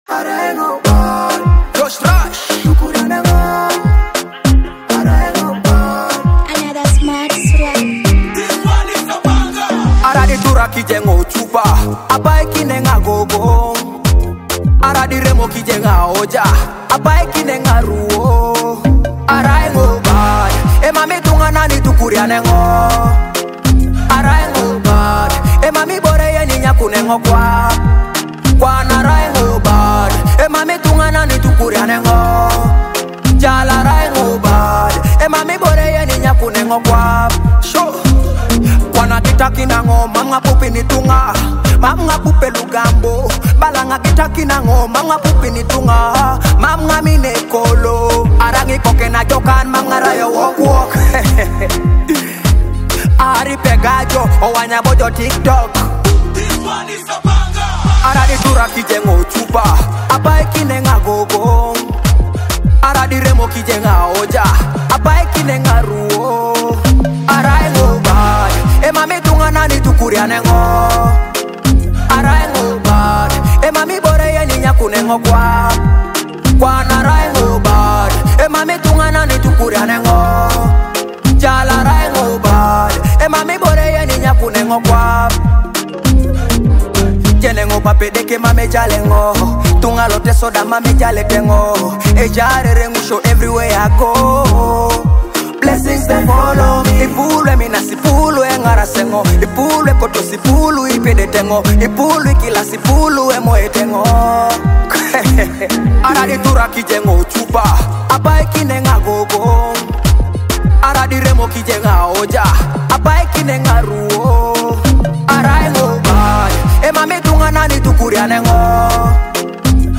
a high-energy Afro-dancehall track with bold Ugandan vibes